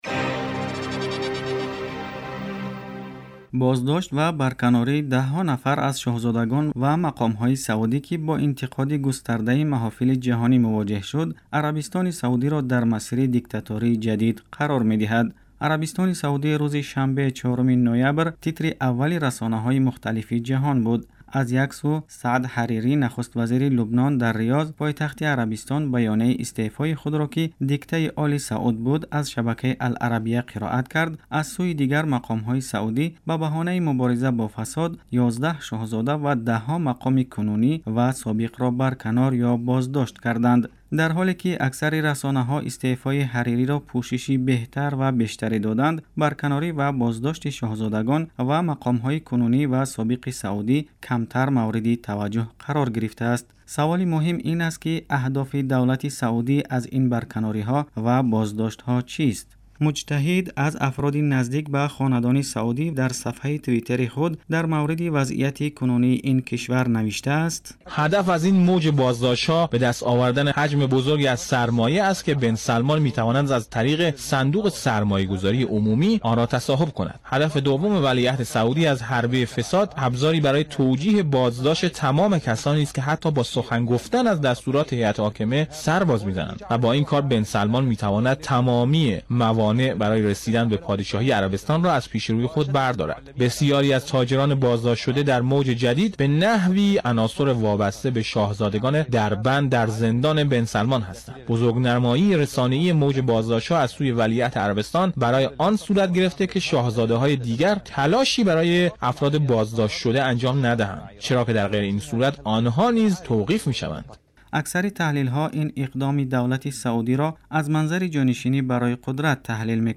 گزارش ویژه- واکنش ها به بازداشت شاهزادگان و مقامات در عربستان